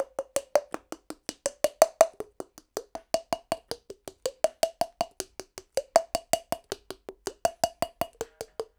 81-PERC3.wav